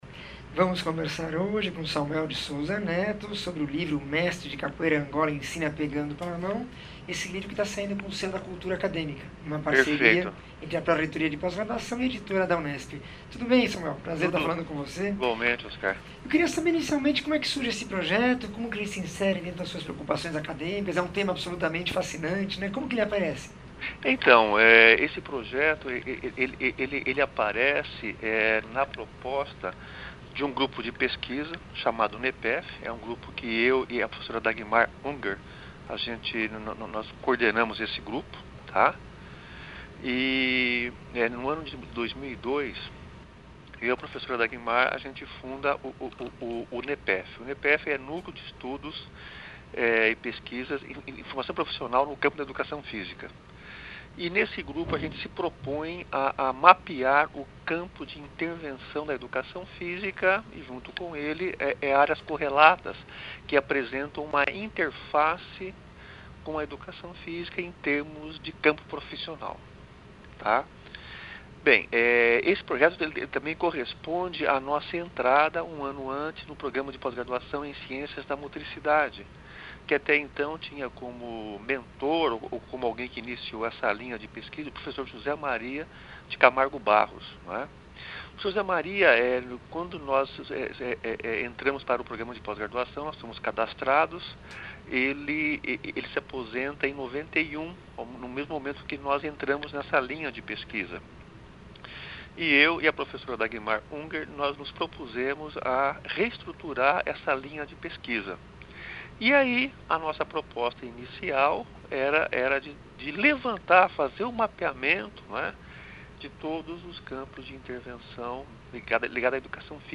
entrevista 1446